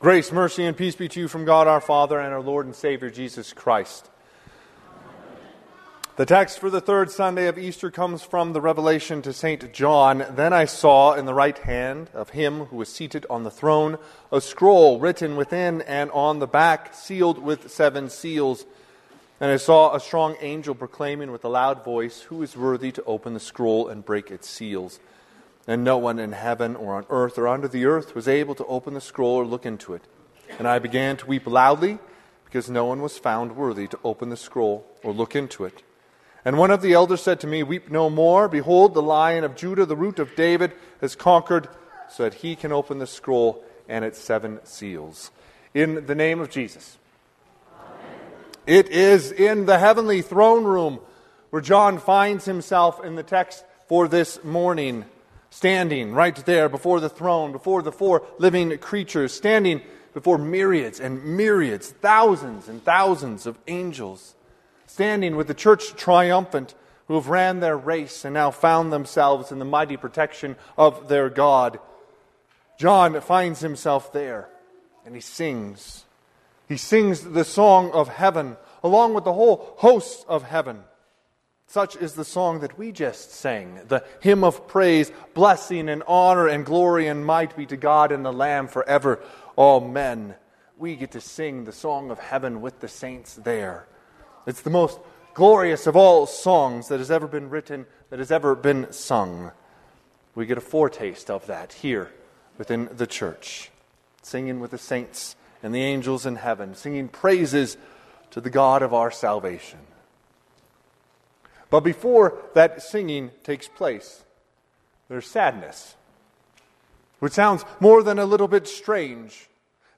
Sermon - 5/4/2025 - Wheat Ridge Evangelical Lutheran Church, Wheat Ridge, Colorado
Third Sunday of Easter
Sermon_May4_2025.mp3